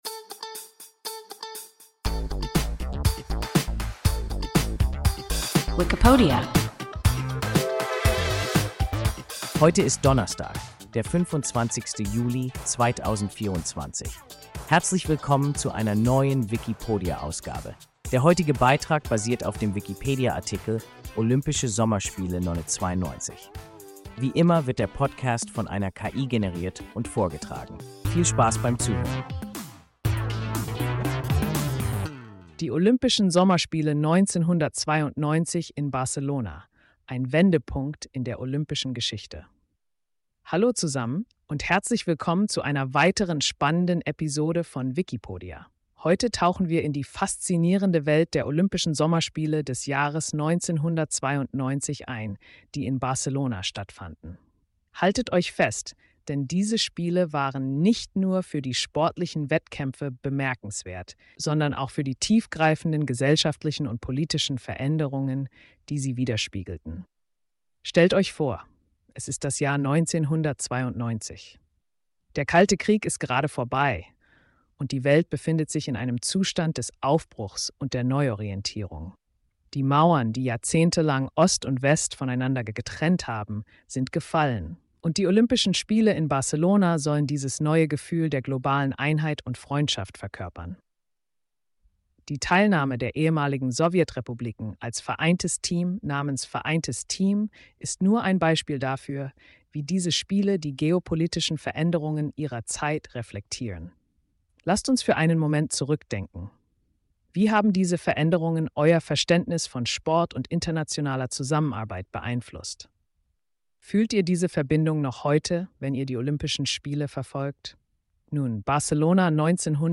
Olympische Sommerspiele 1992 – WIKIPODIA – ein KI Podcast